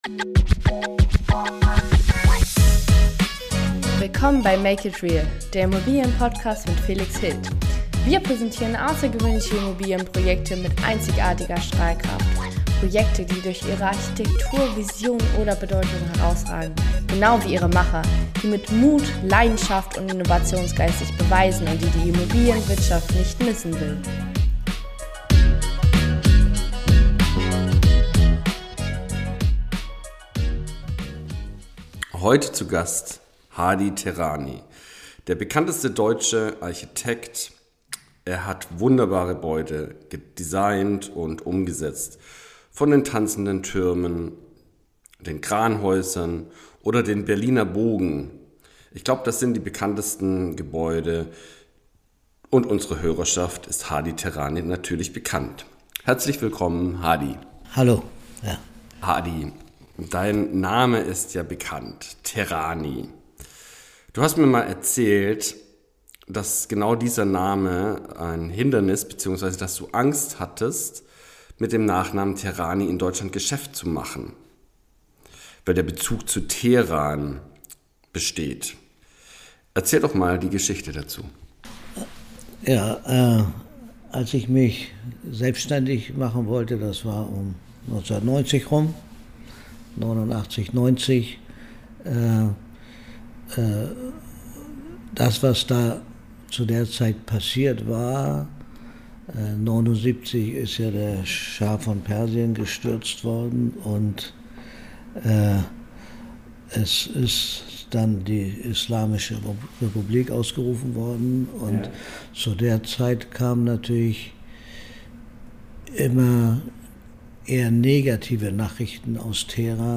In dieser Podcast-Folge habe ich Herrn Hadi Teherani zu Gast. Herr Teherani ist ein international renommierter Architekt, Designer und Unternehmer – mit Schwerpunkt auf ganzheitlicher Gestaltung von Räumen, Gebäuden und Objekten.